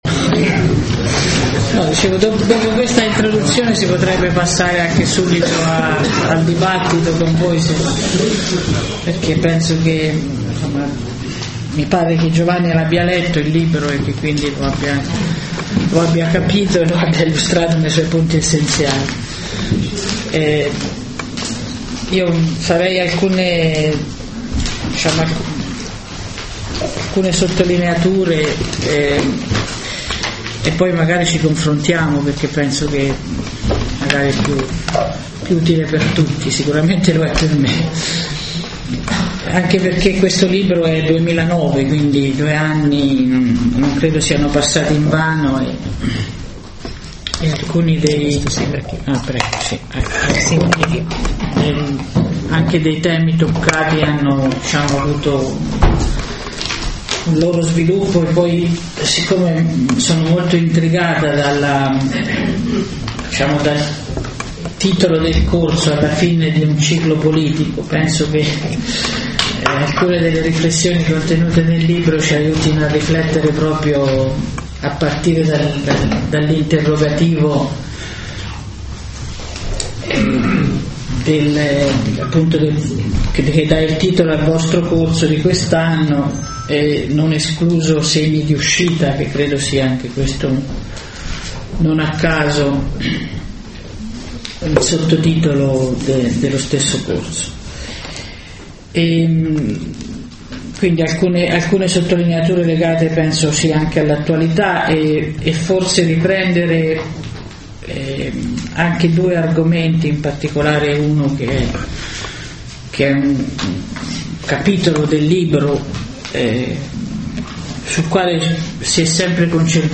Intervento di Rosy Bindi, presentata da Giovanni Bianchi, al Corso di Formazione alla politica 2011-2012 dei Circoli Dossetti di Milano